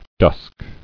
[dusk]